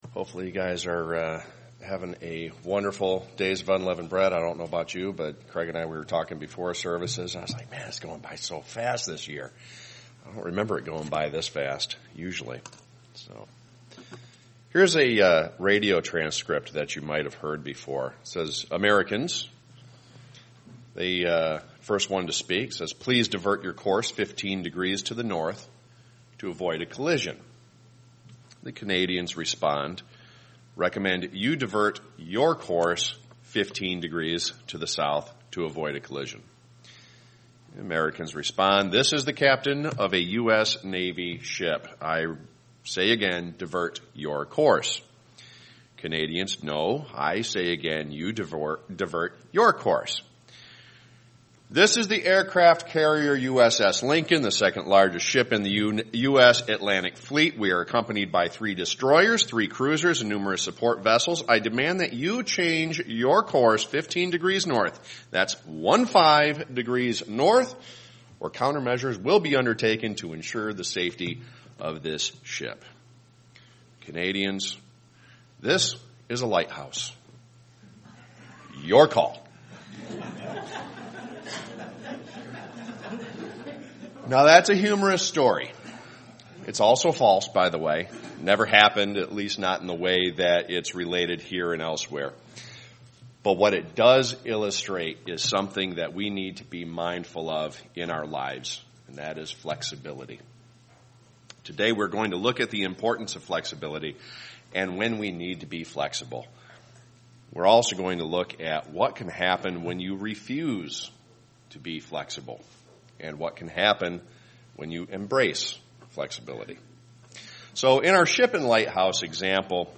Sermons
Given in Freeland, MI